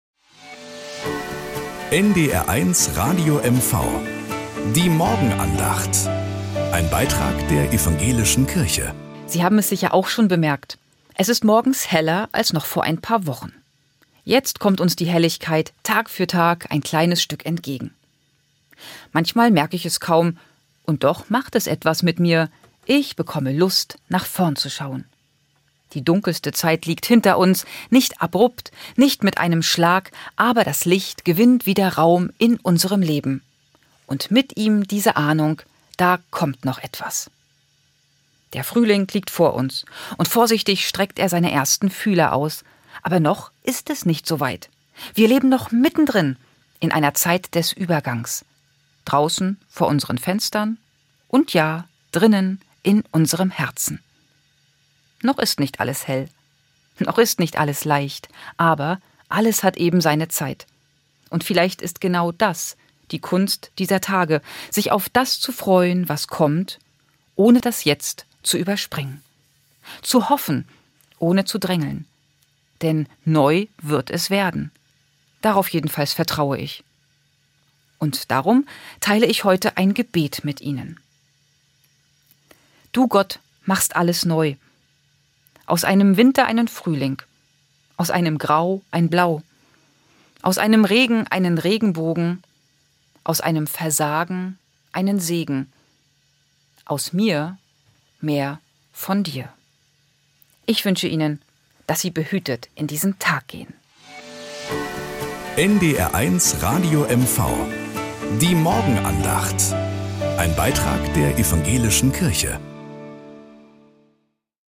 Morgenandacht bei NDR 1 Radio MV